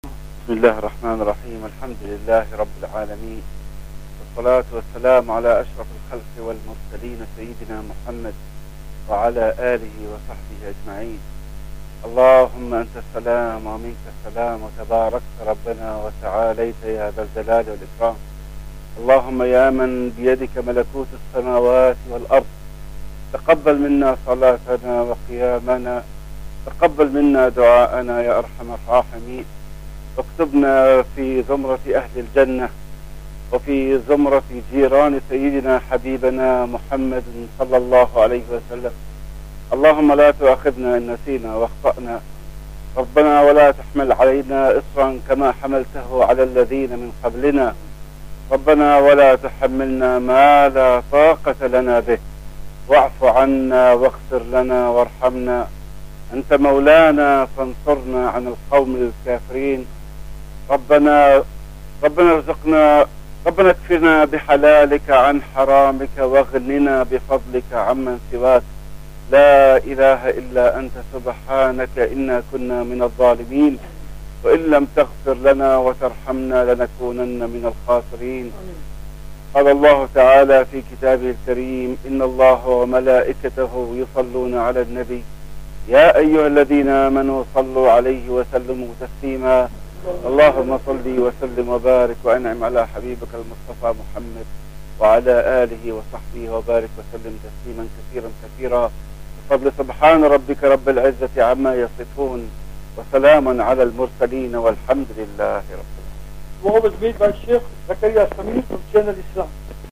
ENGLISH LECTURE, KHUTBA ,JUMU'A SALAAH AND DU'A
Palm Ridge Musjid, Gauteng. South Africa